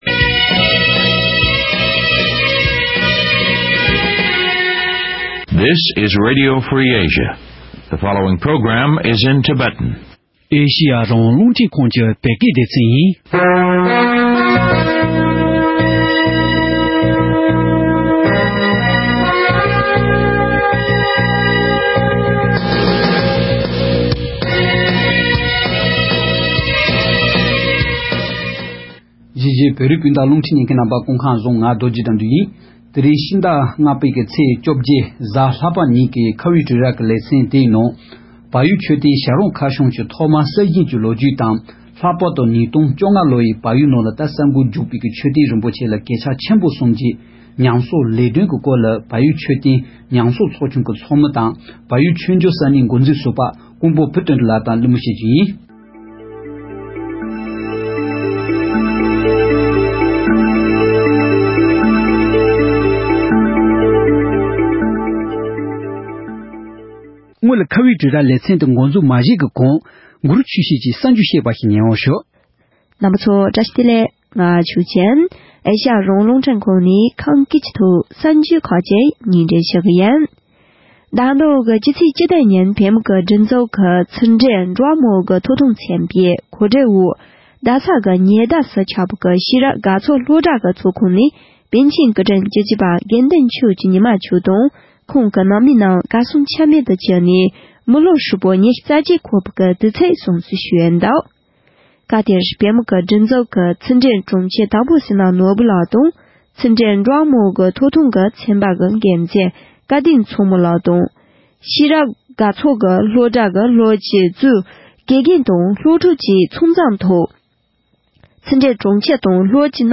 གཉིས་གླེང་མོལ་བྱེད་པ་ཞིག་ཉན་རོགས་ཞུ།